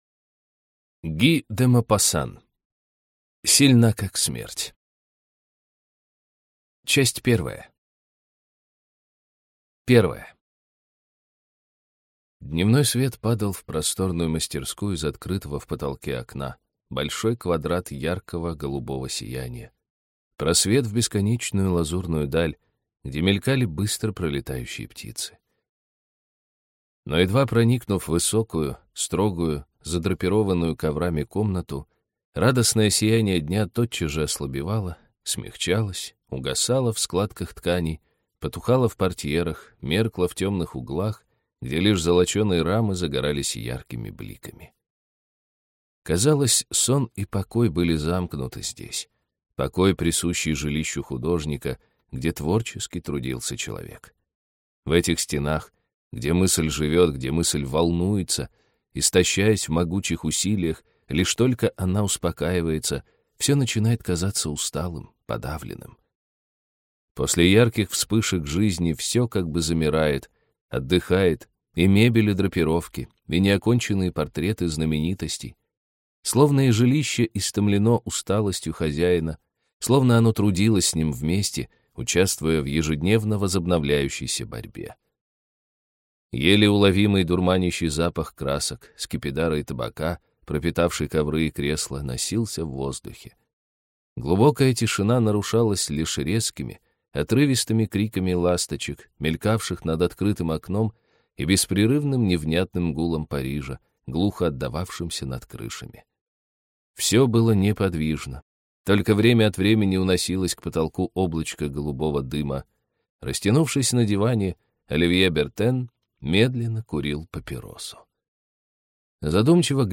Аудиокнига Сильна как смерть | Библиотека аудиокниг